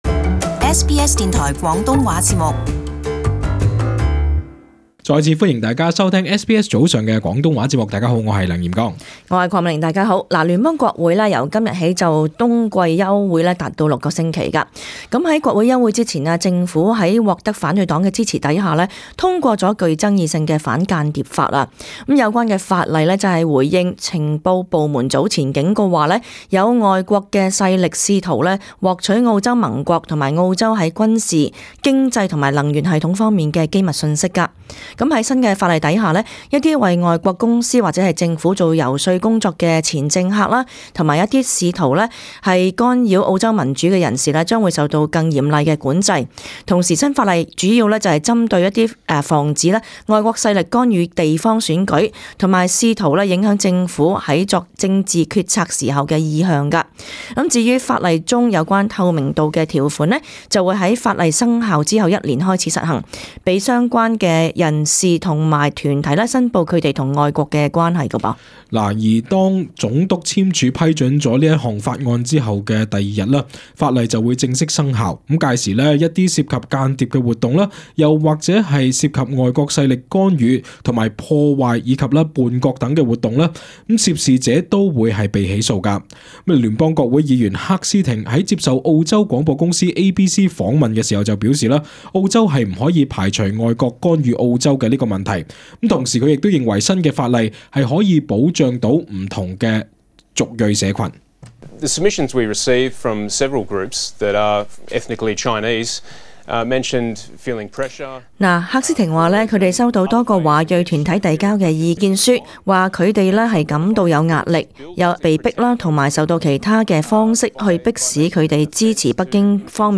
【时事报导】反间谍法案获国会通过